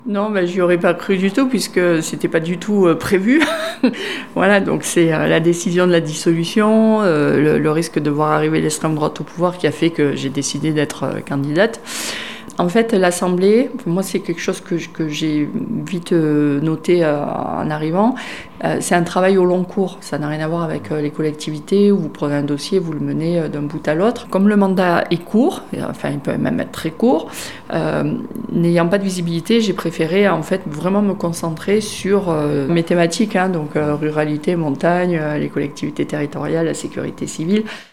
En ce début 2025, l’élue siégeant dans le groupe socialistes et apparentés a accordé une interview pour 48FM et tire un bilan de ces premiers mois en tant que parlementaire. Une nouvelle fonction qu’elle n’imaginait pas avoir il y a un an.